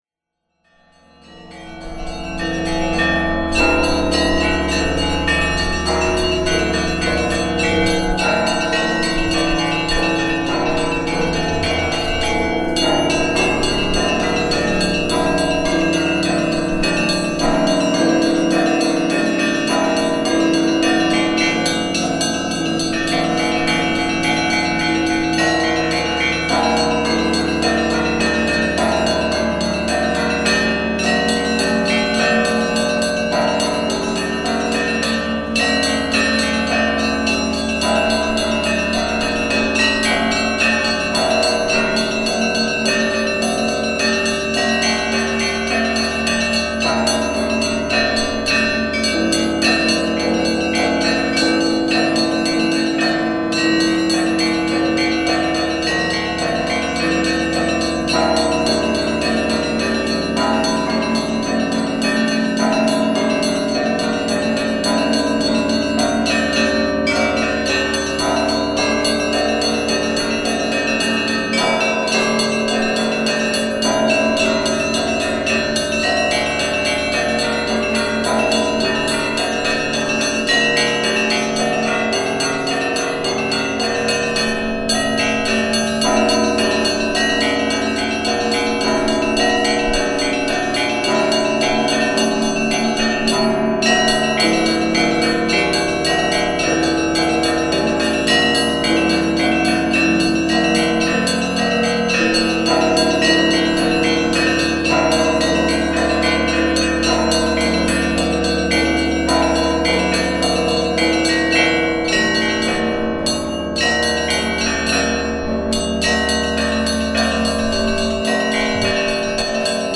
Sound of bells (peal).
bell bells cathedral chime chimes church church-bell church-bells sound effect free sound royalty free Animals